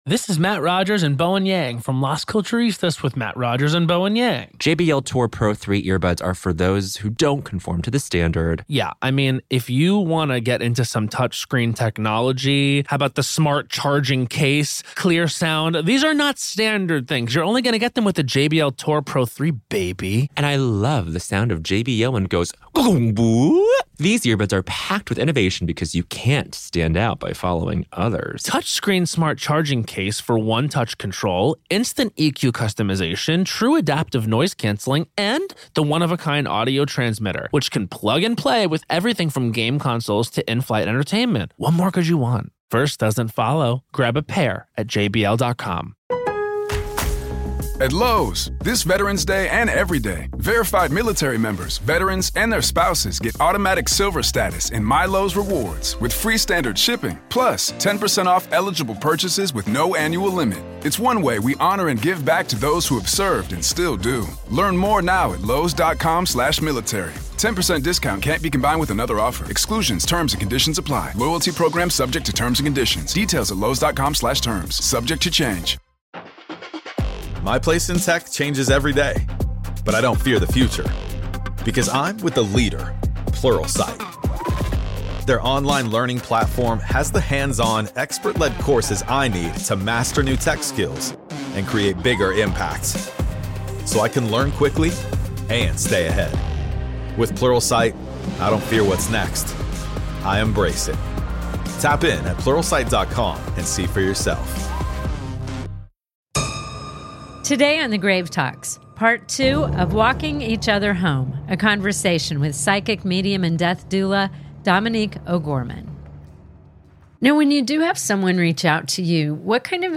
Because in the end, the dead don’t just remind us how to die — they teach us how to truly live. This is Part Two of our conversation.